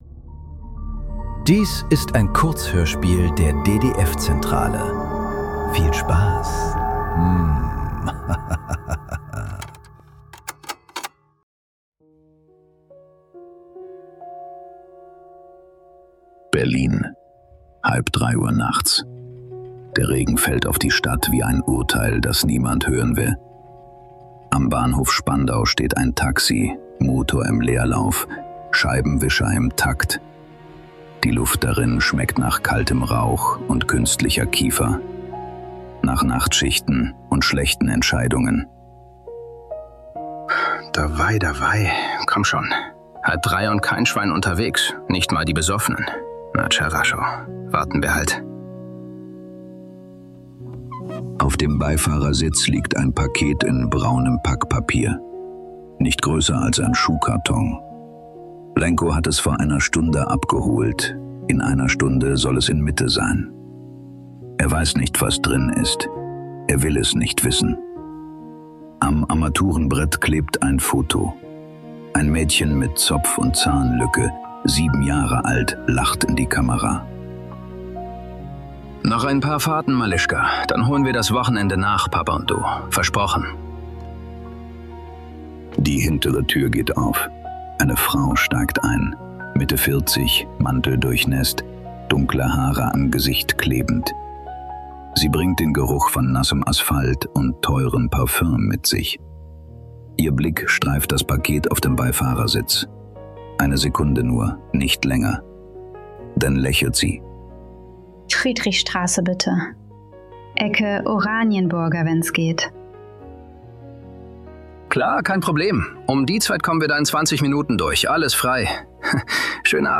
Nachtfahrt ~ Nachklang. Kurzhörspiele.